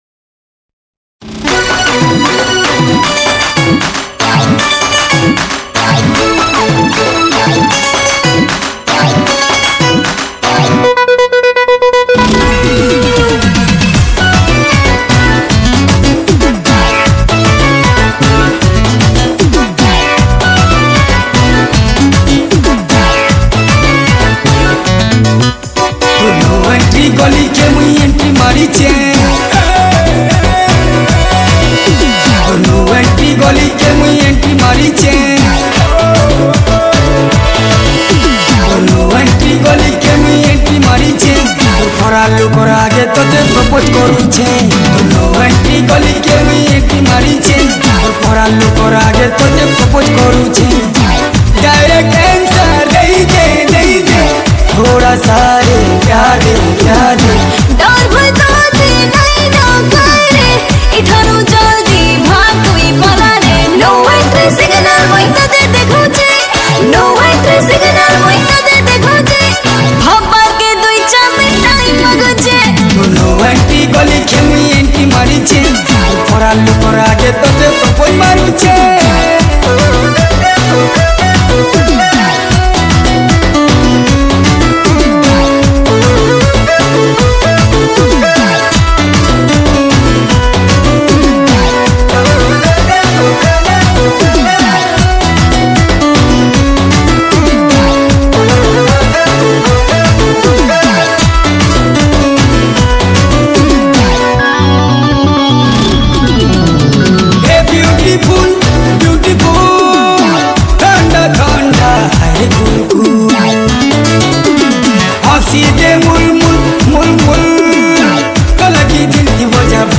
Sambalpuri Super Hit Song